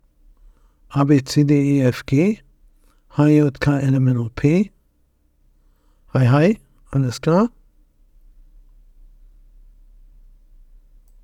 Tascam auf Audac_direkt Test 1 (enhanced).wav